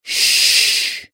Phonics Soundboard922 views